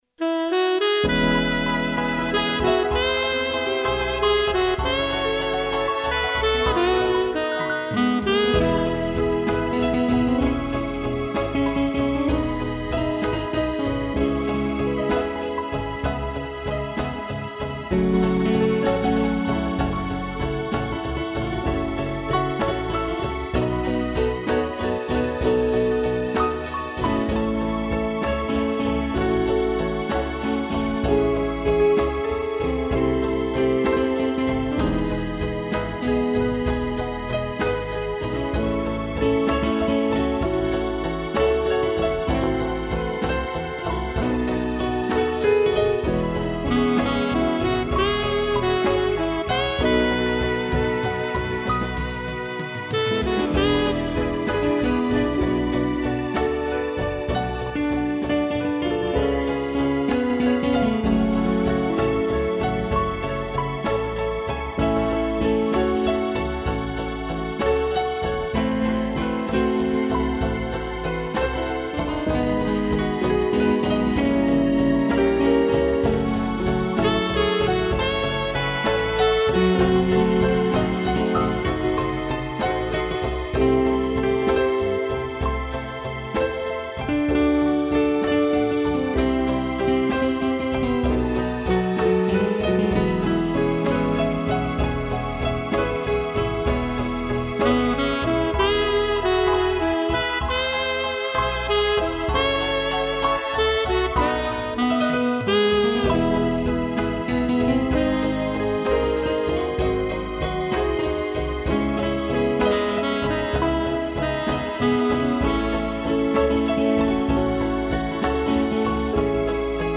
piano e sax
(instrumental)